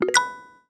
new_message.mp3